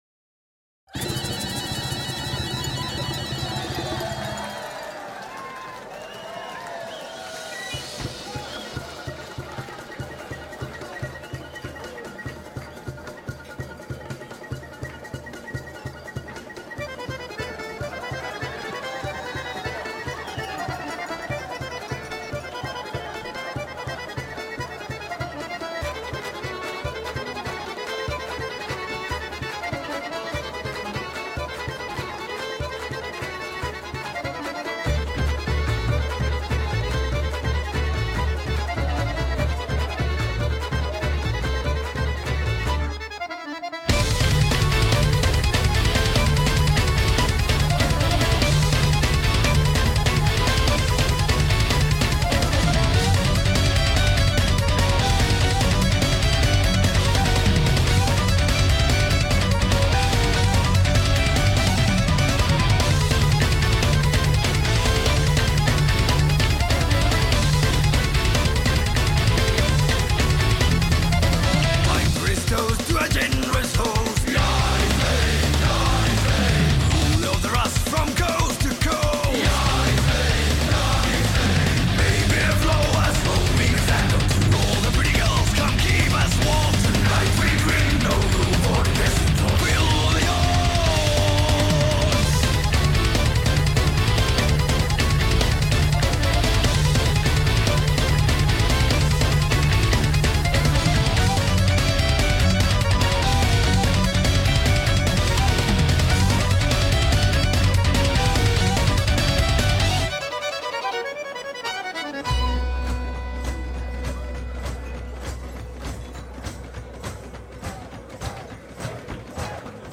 (And a fan of heavy metal accordion, of course.)
Posted April 20th, 2009 in Folk, MP3s, Rock · 2 Comments